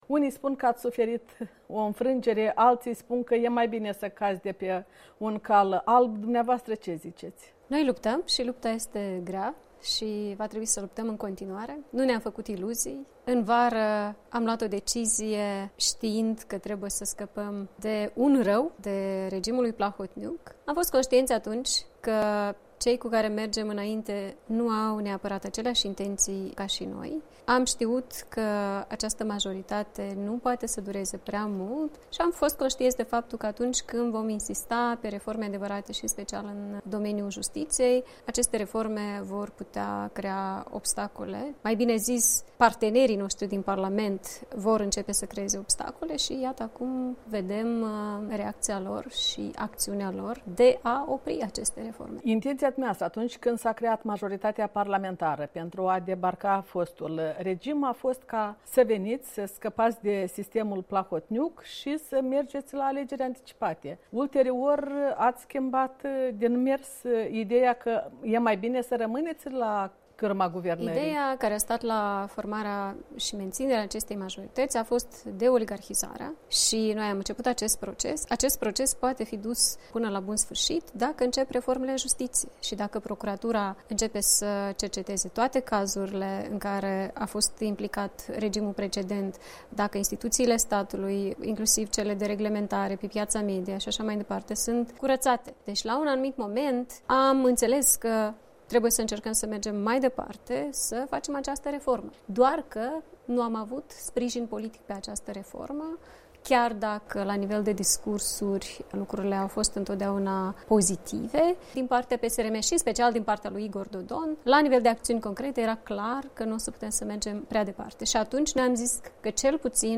Interviu cu șefa guvernului în exercițiu, lidera Blocului ACUM.